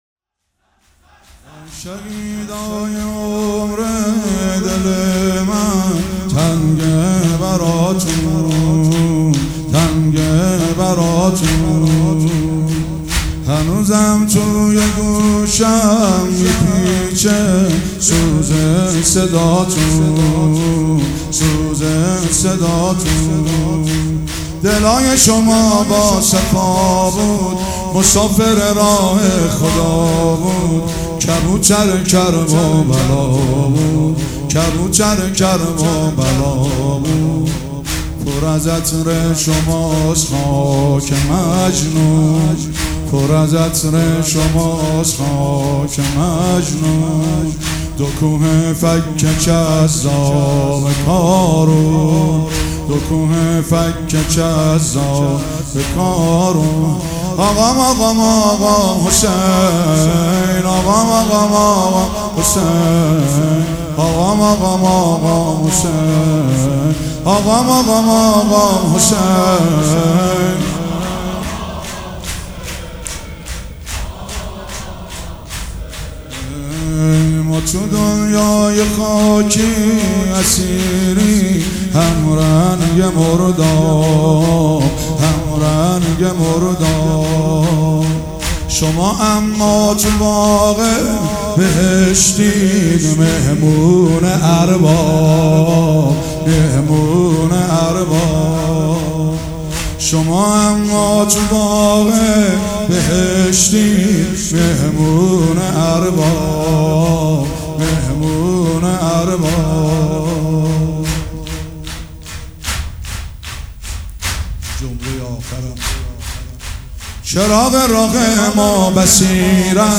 شور
مراسم عزاداری شب پنجم